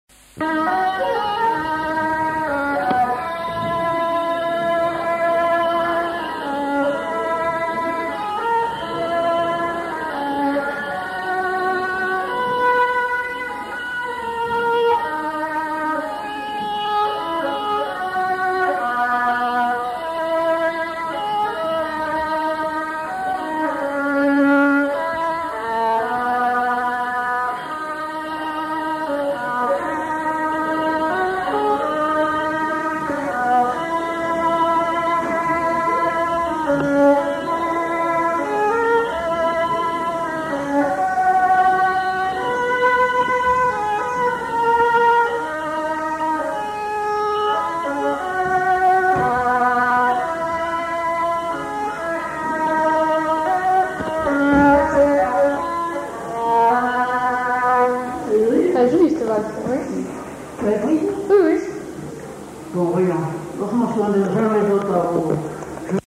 Valse
Aire culturelle : Haut-Agenais
Lieu : Cancon
Genre : morceau instrumental
Instrument de musique : violon
Danse : valse